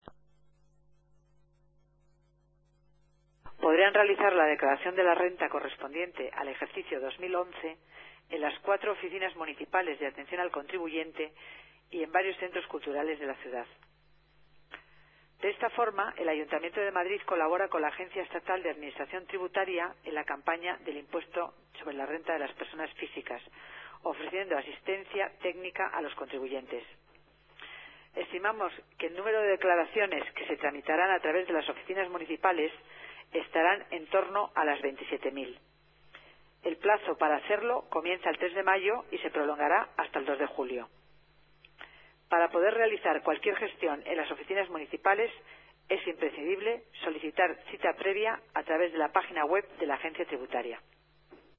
Nueva ventana:Declaraciones de Concepción Dancausa, delegada de Hacienda